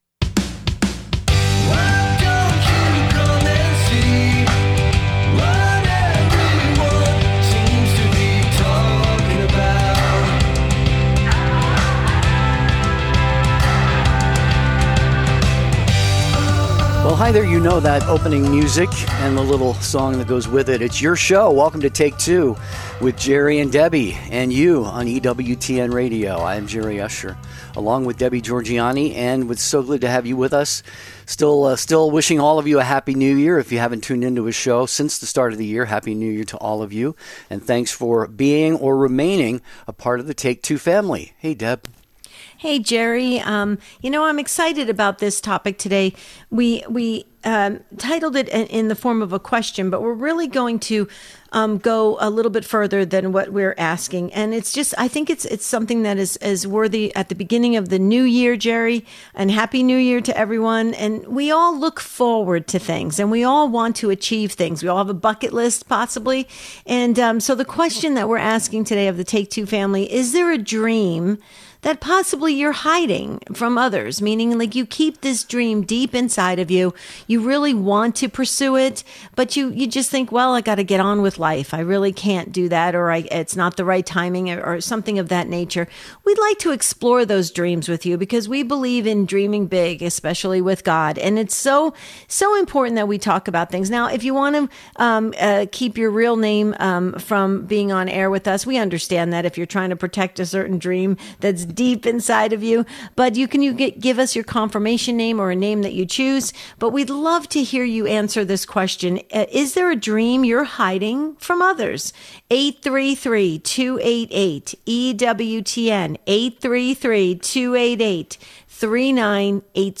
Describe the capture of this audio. a unique live daily call-in show